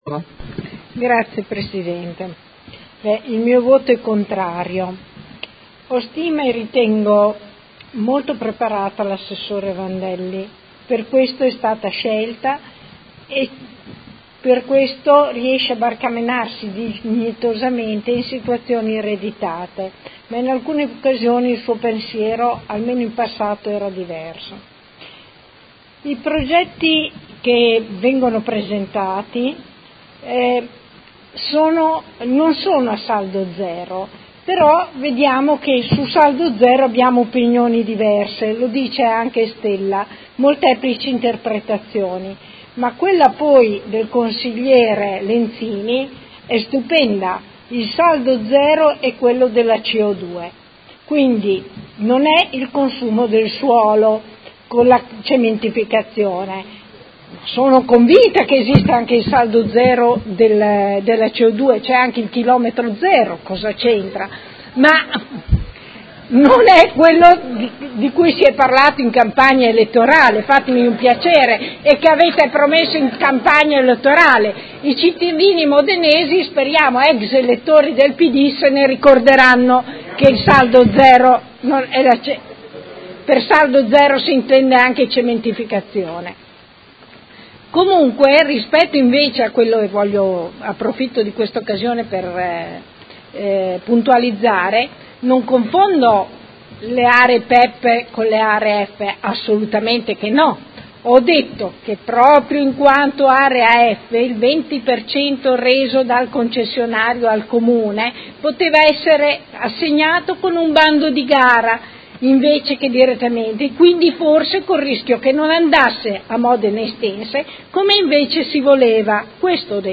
Seduta del 19/07/2018 Dichiarazione di voto.